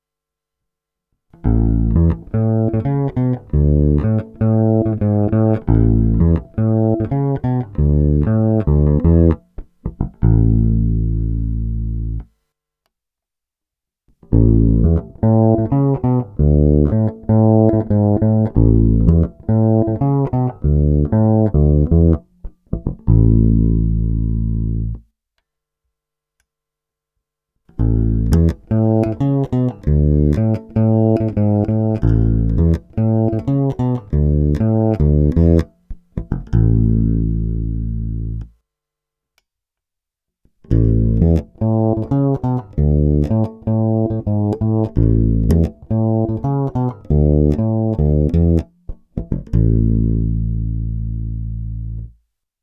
Není-li uvedeno jinak, následující nahrávky byly provedeny rovnou do zvukové karty, korekce basů, středů i výšek byly přidány cca na 1/2, tónová clona vždy plně otevřená. Hráno vždy blízko krku.
První tři ukázky mají vždy čtyři části v pořadí: 1) singl, 2) humbucker, 3) singl + piezo, 4) humbucker + piezo, přičemž mix pieza a magnetických snímačů byl cca 50/50.
Kobylkový snímač